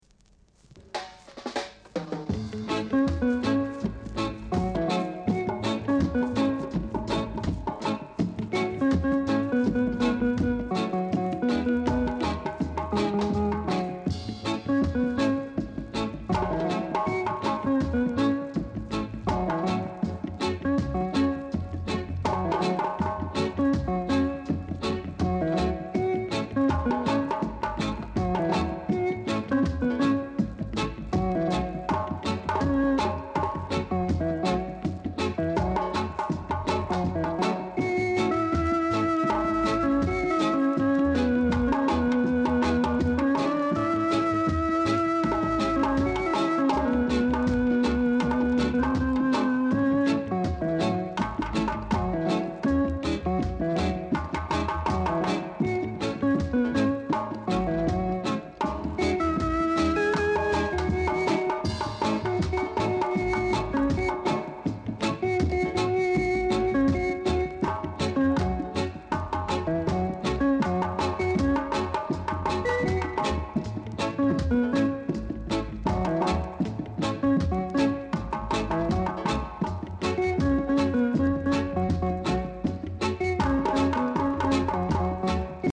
Rock Steady
R. Steady Inst
Very rare! great rock steady inst & vocal!